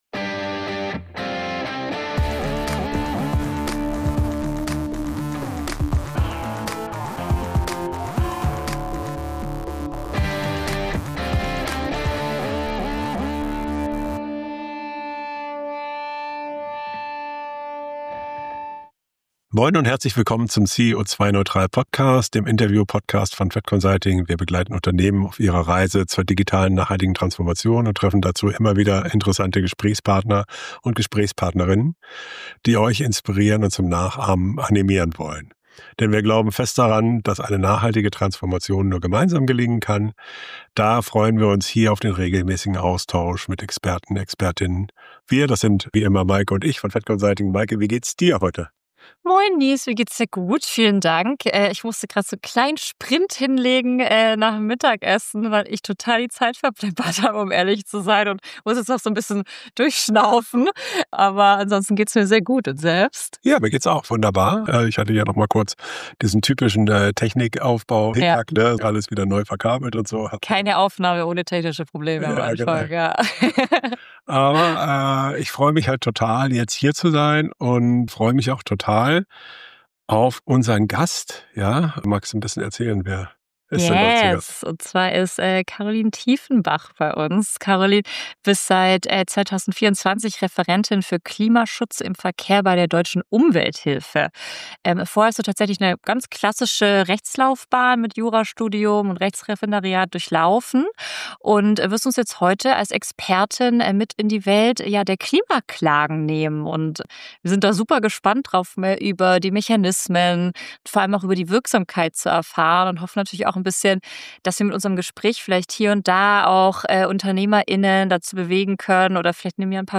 Klimaklagen der Deutschen Umwelthilfe: Lästig für die Wirtschaft oder ein wirkungsvoller Hebel für mehr Nachhaltigkeit ~ CEO2-neutral - Der Interview-Podcast für mehr Nachhaltigkeit im Unternehmen Podcast